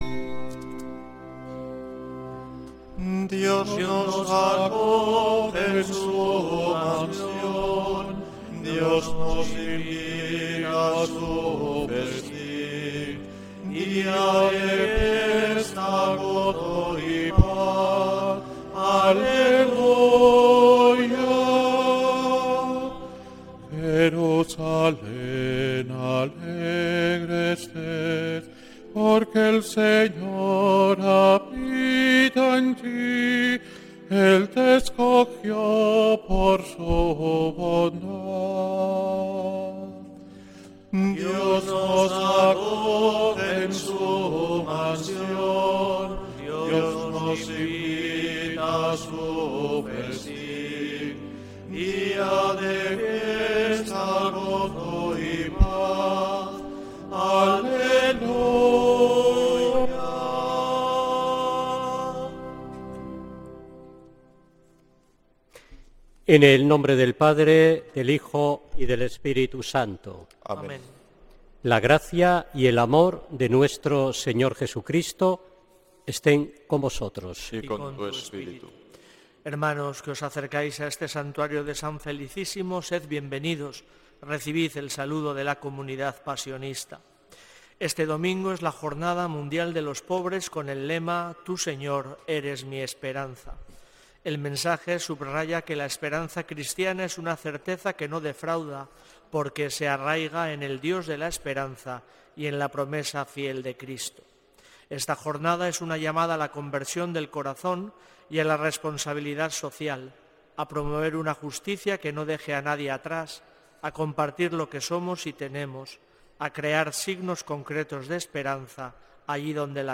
Santa Misa desde San Felicísimo en Deusto, domingo 16 de noviembre de 2025